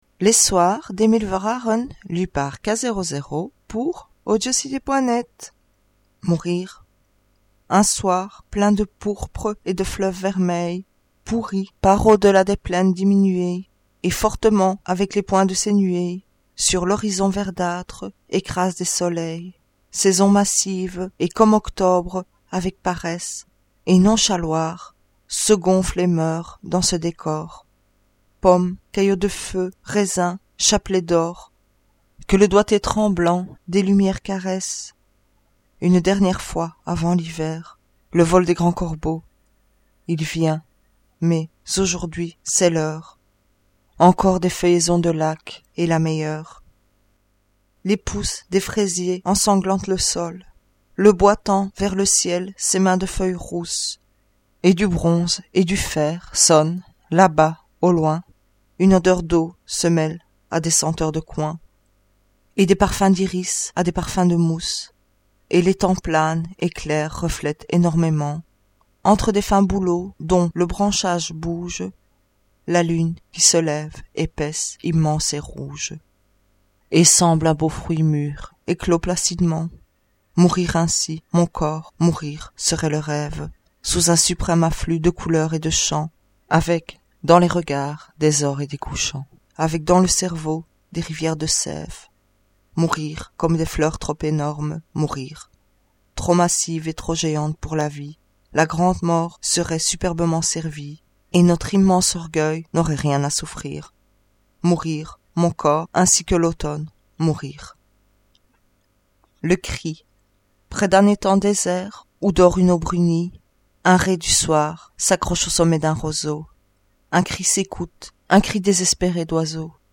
Poésie